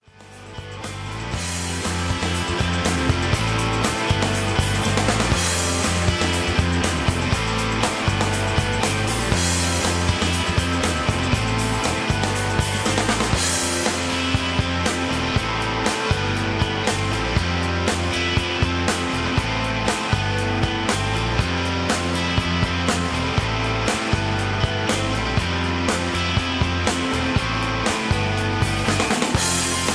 Tags: sound tracks , rock